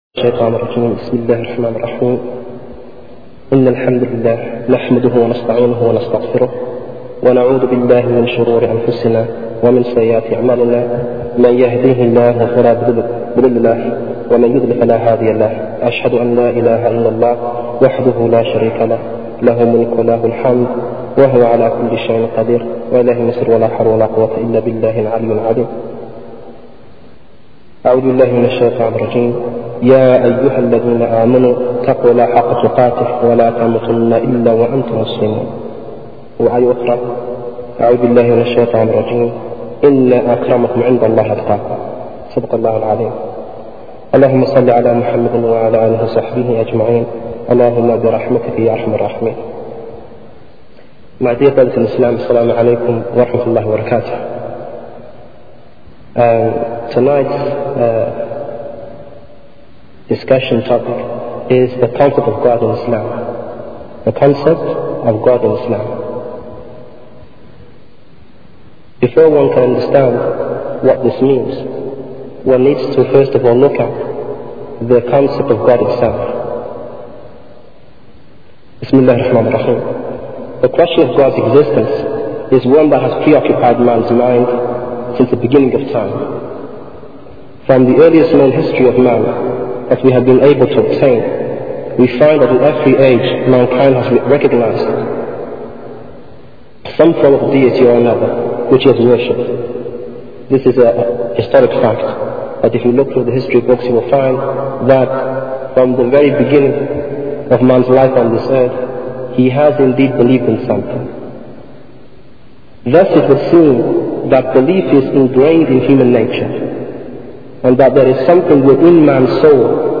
A lecture